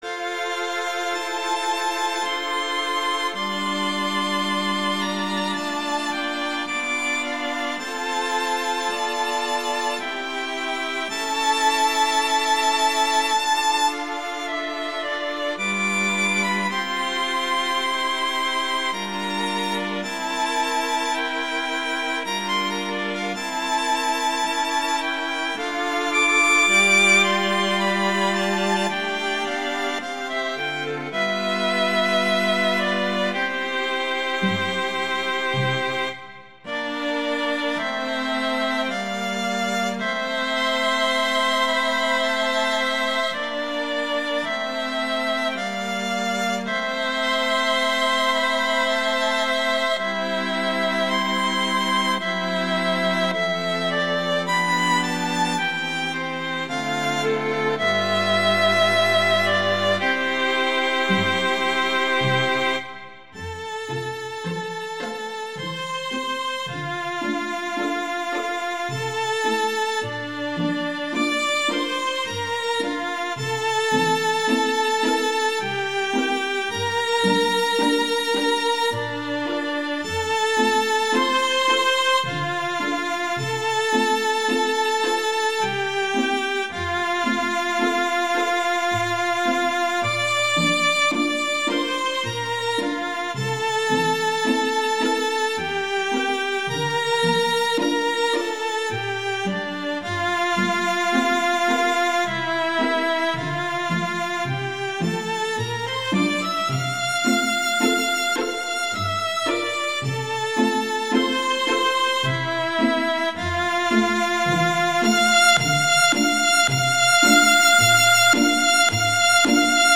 Instrumentation: string quartet
classical, wedding, festival, love
F major
♩=54 BPM